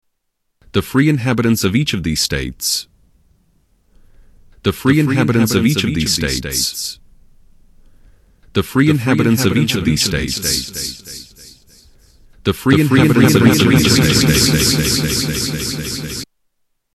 Echo sample
Category: Animals/Nature   Right: Personal